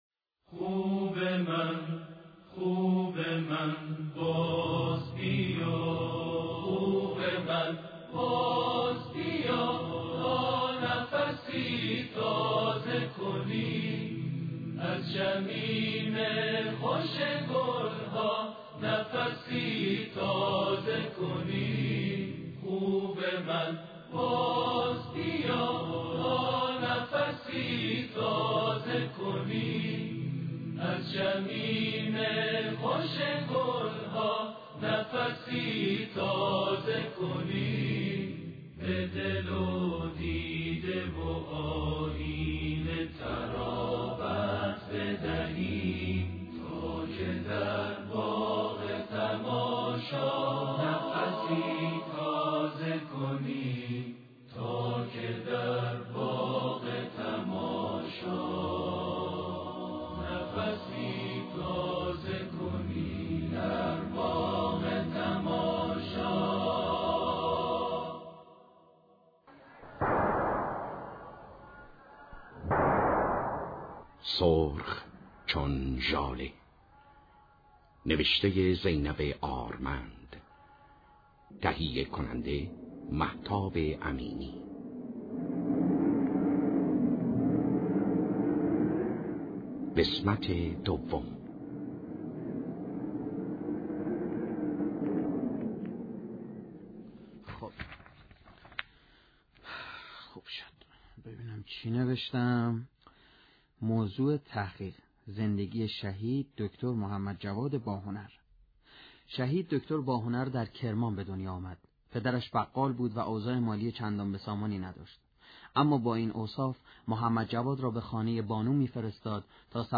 نمایش رادیویی - سرخ چون لاله